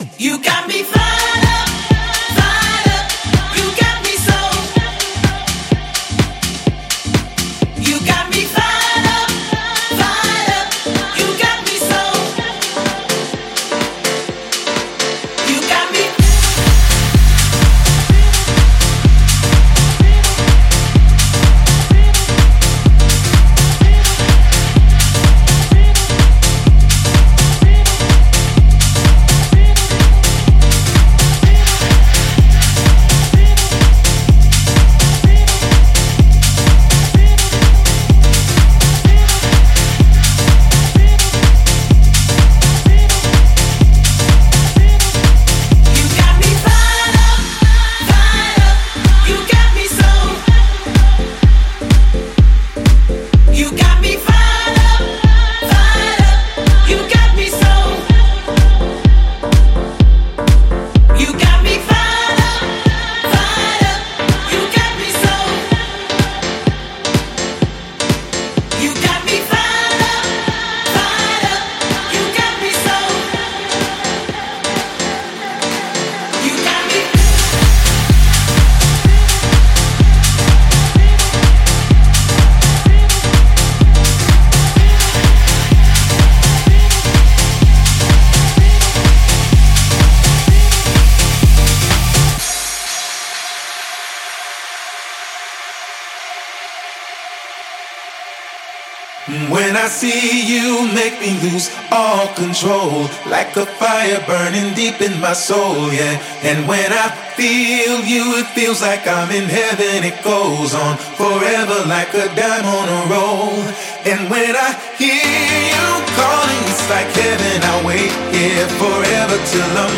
DJ Mixes and Radio Show Episodes.